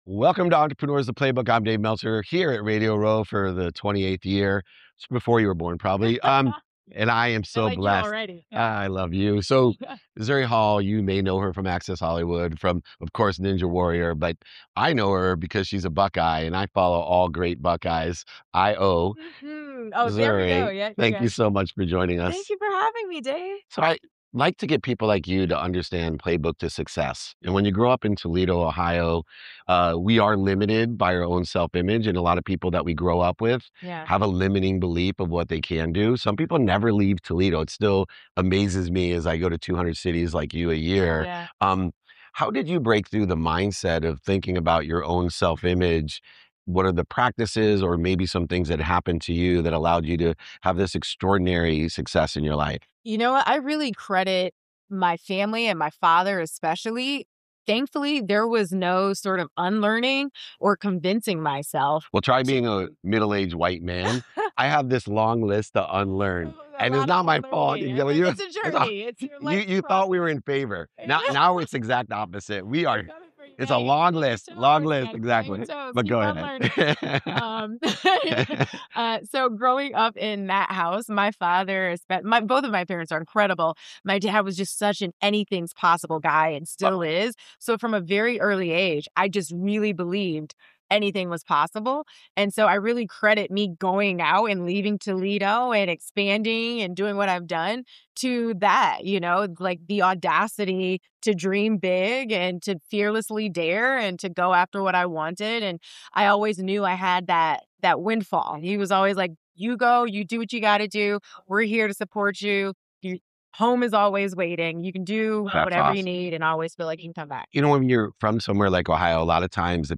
In today’s episode, I sit down at Radio Row with Zuri Hall, an American TV personality and actress known for Access Hollywood and American Ninja Warrior. We talk about growing up in Toledo, Ohio, and how her father’s belief that anything is possible shaped her self-image and career. Zuri shares how she reconciles the “on camera” brand with who she really is, and why authenticity has become her greatest asset.